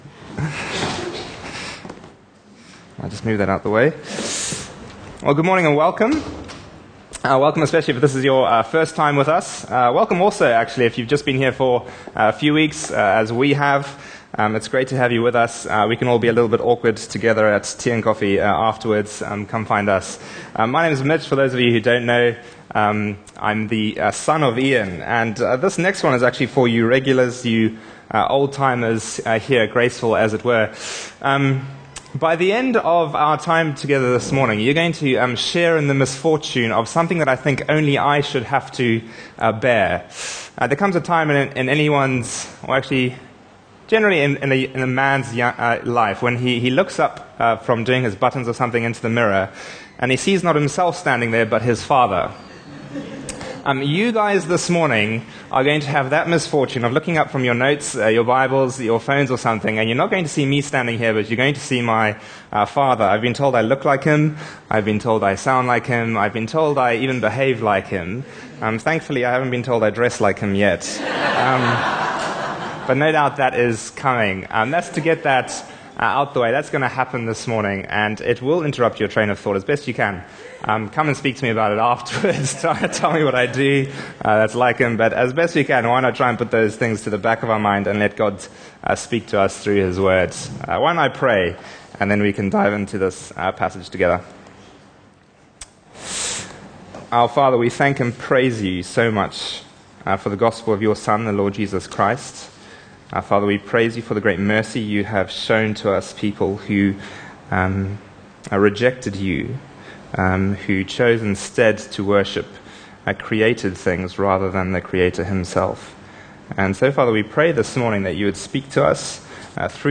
Bible Talks Bible Reading: Romans 12:1-2